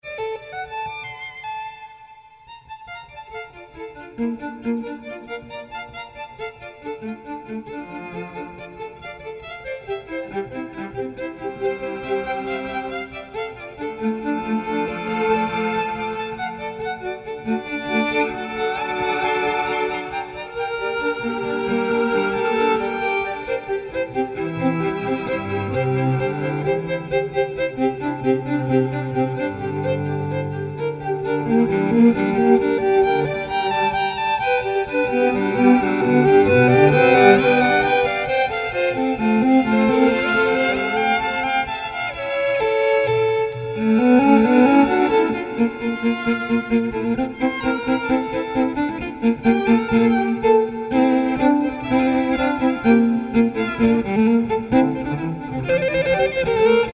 Violin / Effects